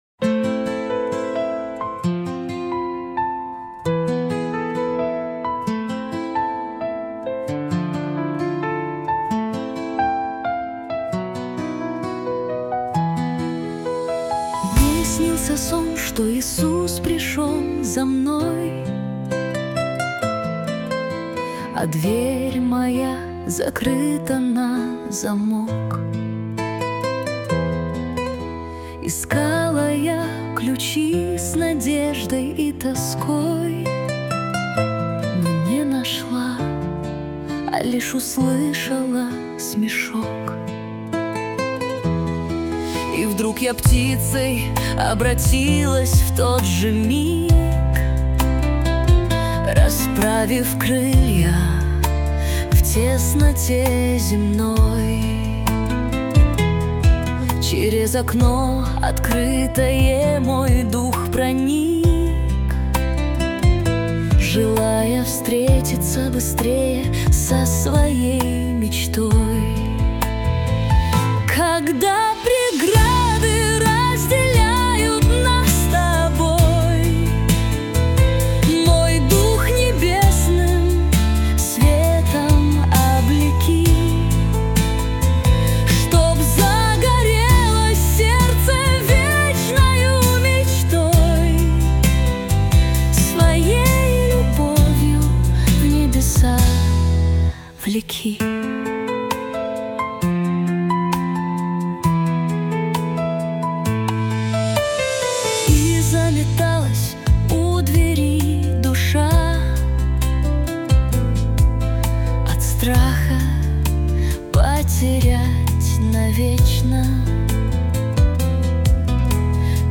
песня ai
180 просмотров 921 прослушиваний 34 скачивания BPM: 67